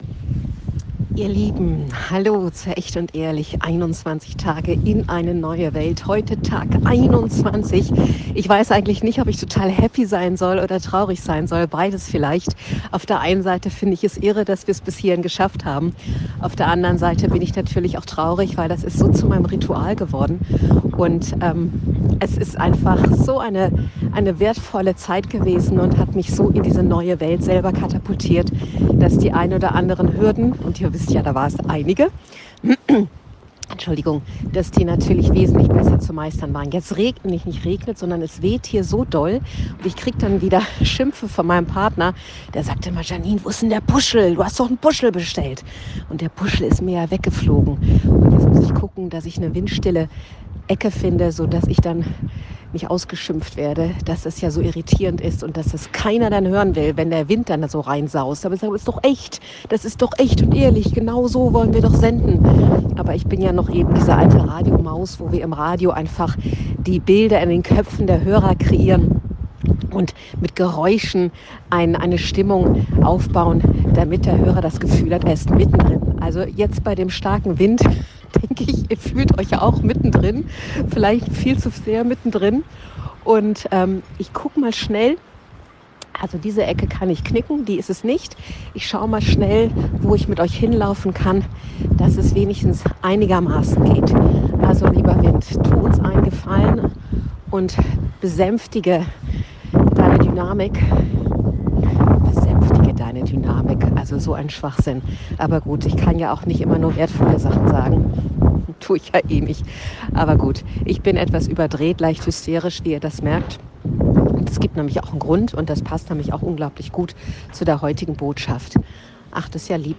Was stört uns schon der kleine Sturm im Hintergrund beim Zuhören.